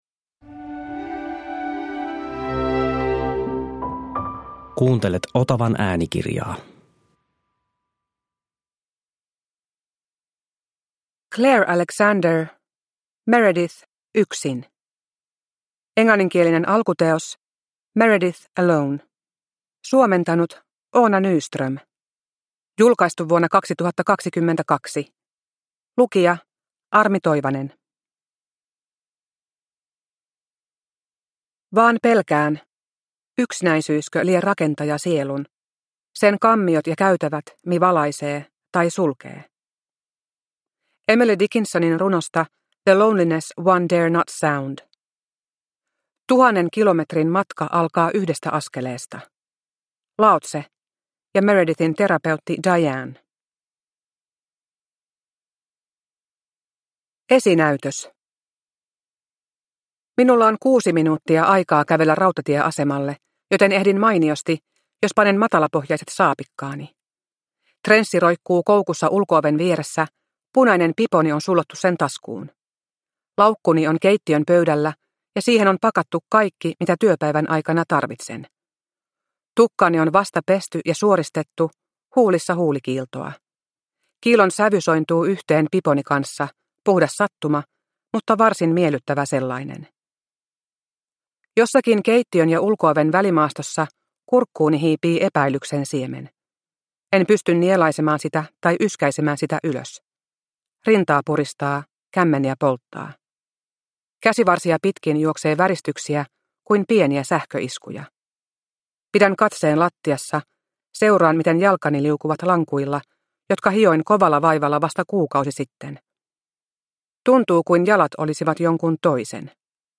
Meredith, yksin – Ljudbok – Laddas ner